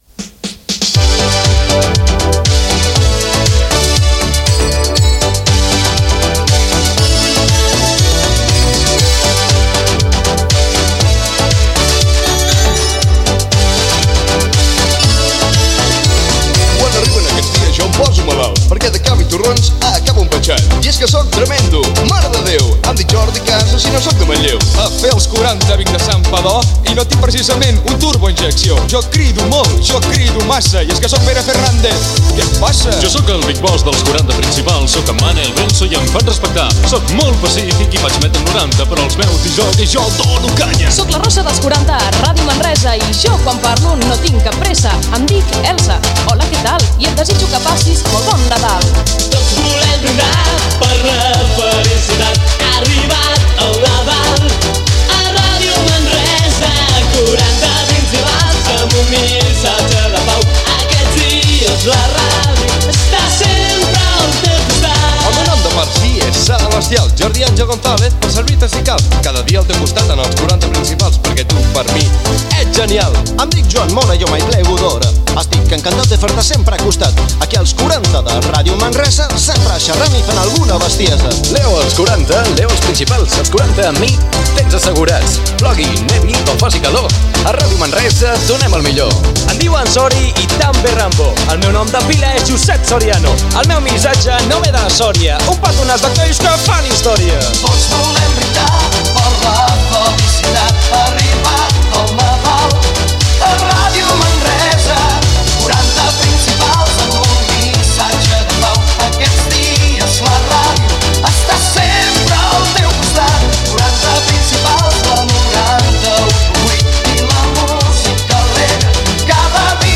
Rap de Nadal
Musical